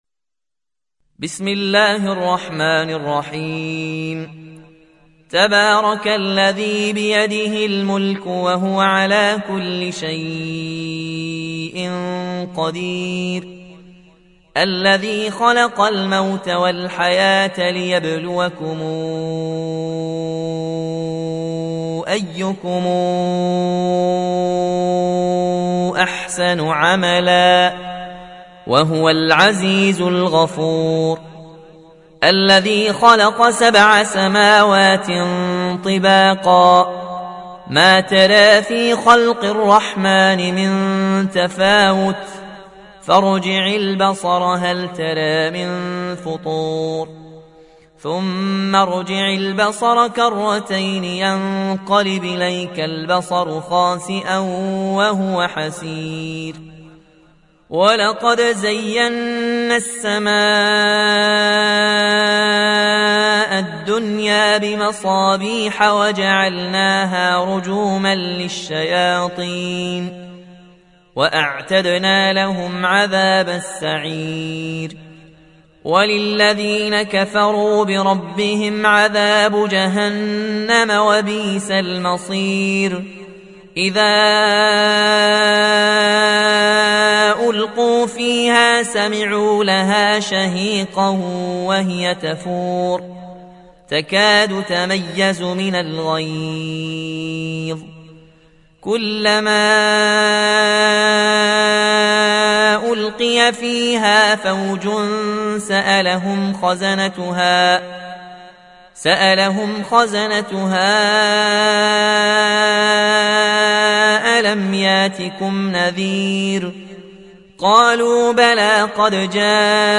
(Riwayat Warsh)